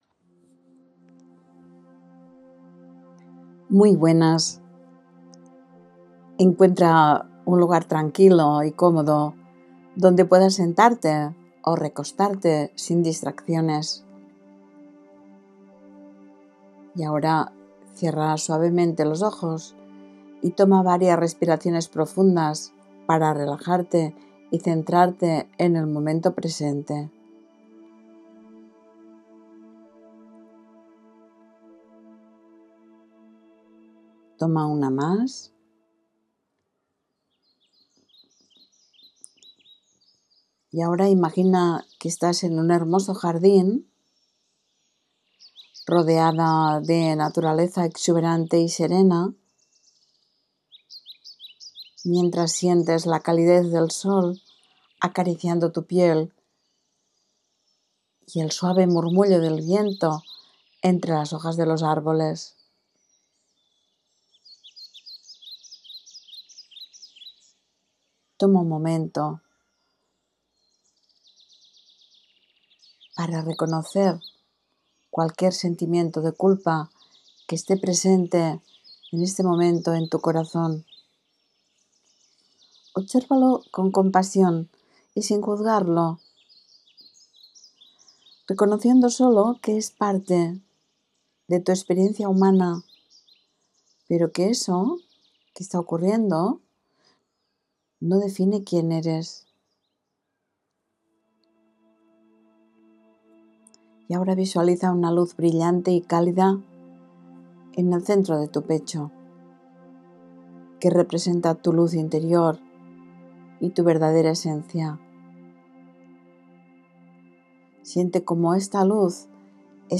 Visualización: «comienza a soltar los lazos que te atan a la culpa»
Recuerda que puedes realizar esta visualización guiada siempre que lo necesites para soltar los lazos que te atan a la culpa para abrirte a una mayor paz interior y libertad emocional.